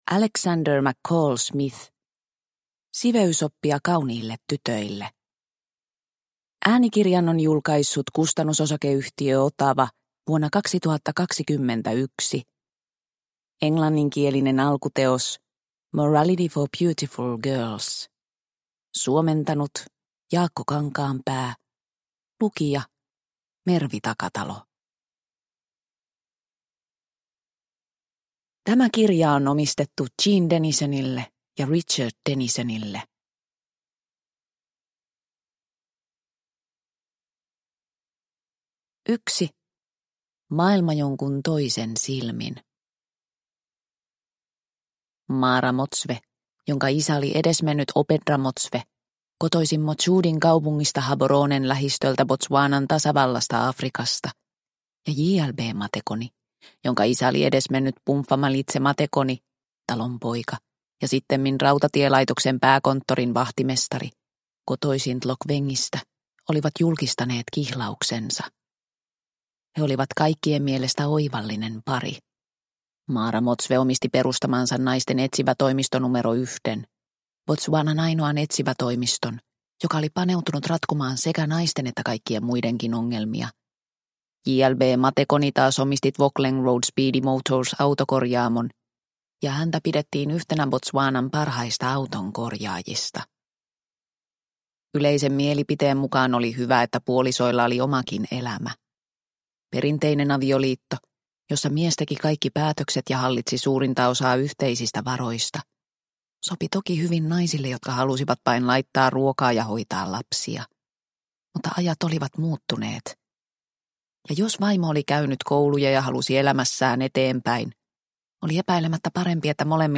Siveysoppia kauniille tytöille – Ljudbok – Laddas ner